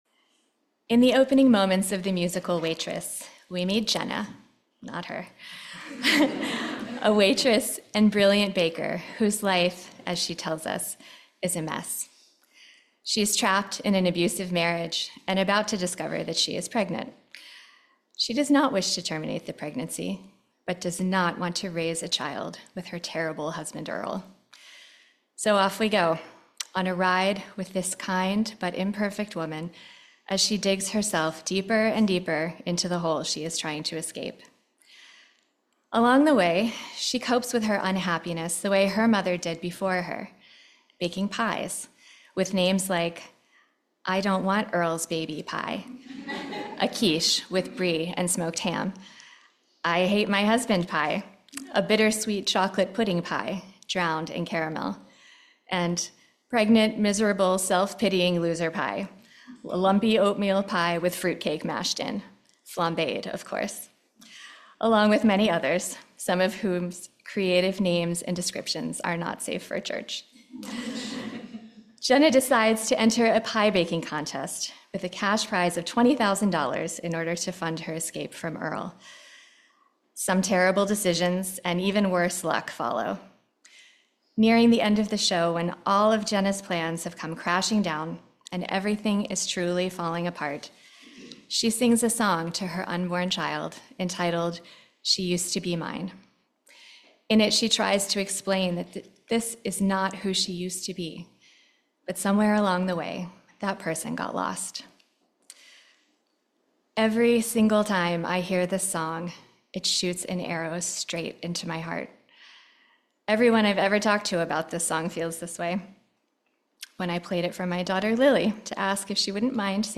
This sermon uses the musical Waitress and its poignant song, “She Used to Be Mine,” as a starting point to explore common feelings of inadequacy and loss of identity